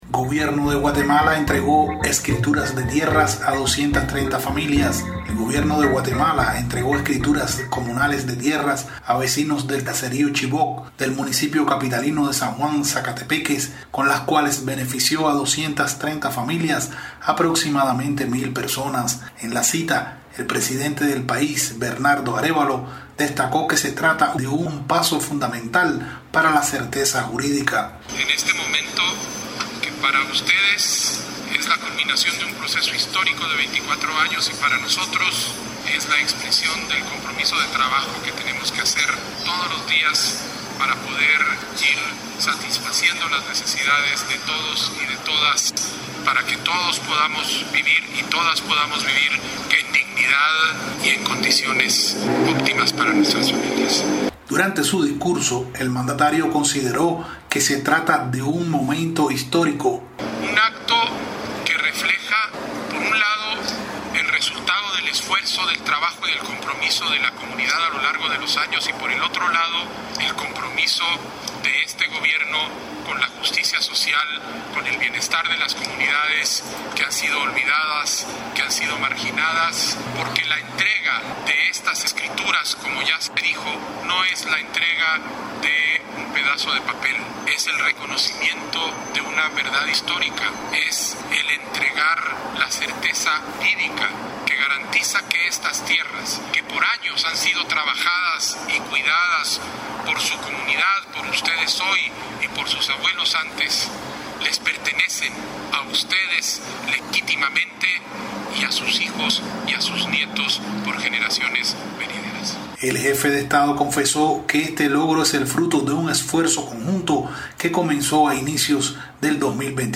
desde Ciudad de Guatemala